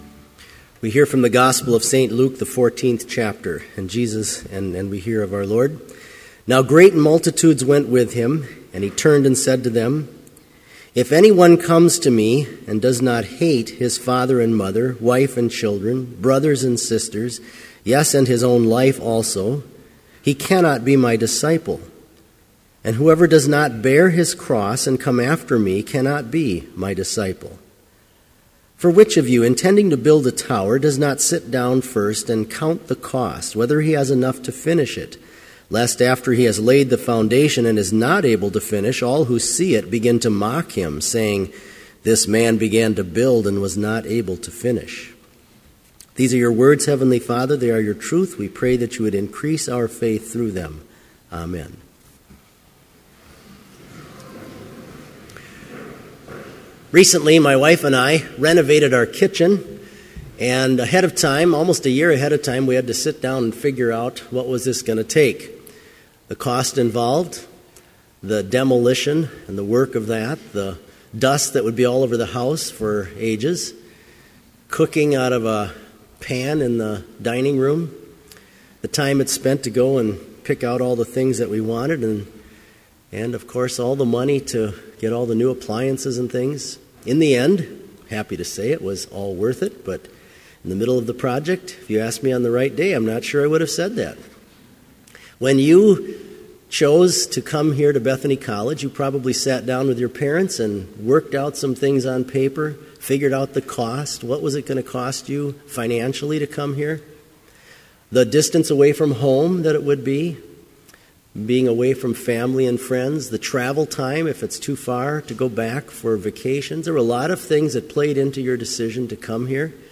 Complete service audio for Chapel - November 22, 2013